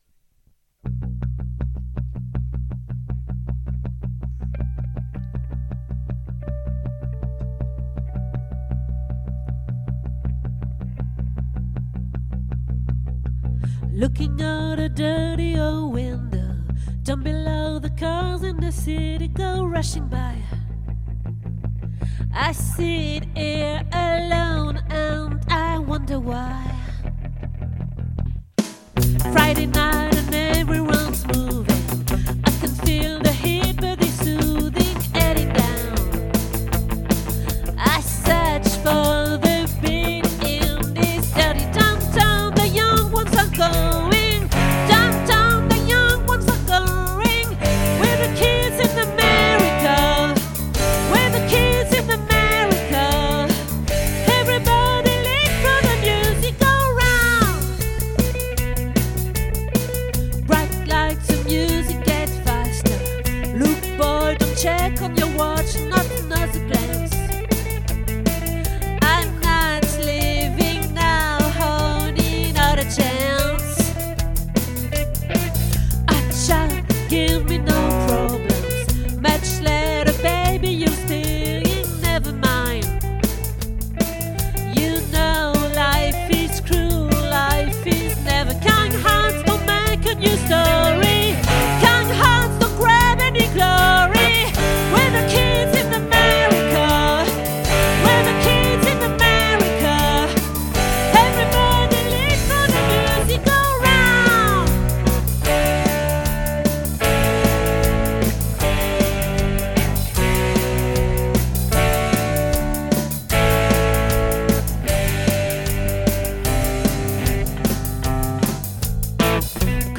🏠 Accueil Repetitions Records_2024_01_29